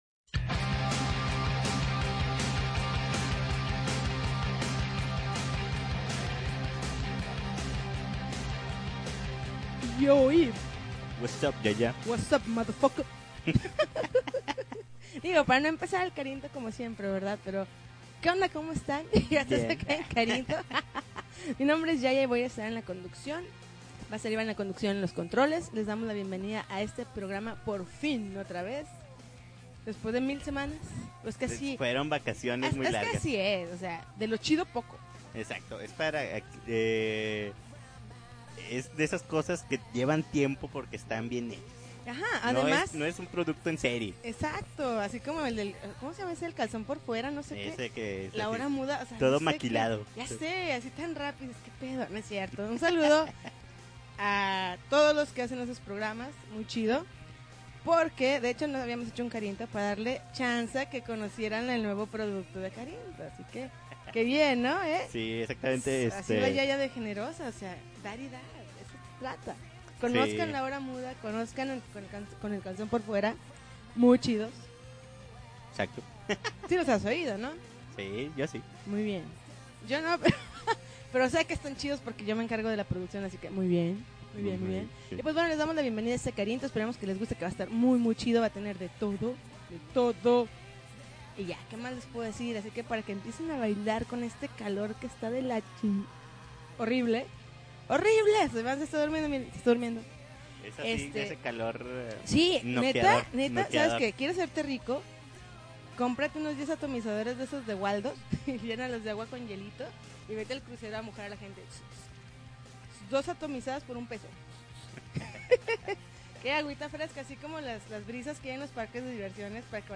50 grados celcius de pura musica chida!